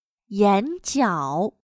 眼角/Yǎnjiǎo/rabillo del ojo